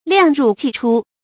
量入計出 注音： ㄌㄧㄤˋ ㄖㄨˋ ㄐㄧˋ ㄔㄨ 讀音讀法： 意思解釋： 見「量入為出」。